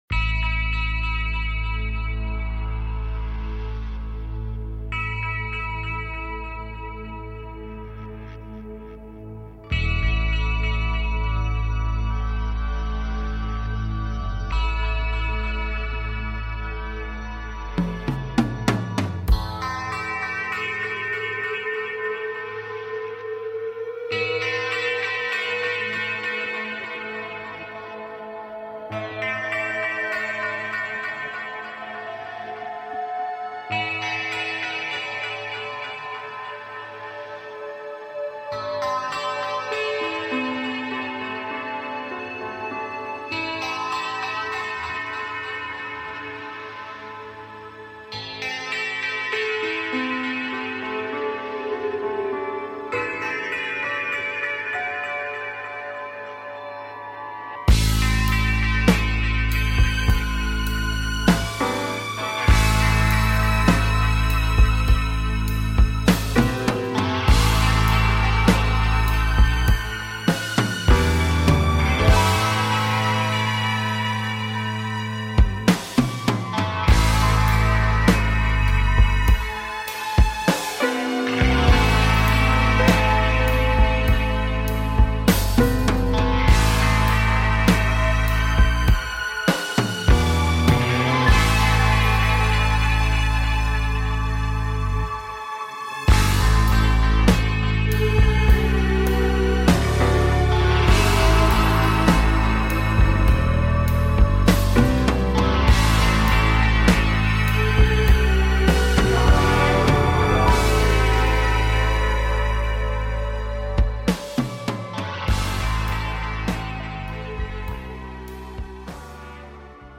Talk Show Episode, Audio Podcast
A show based on Timelines and manifesting Timelines while taking caller questions.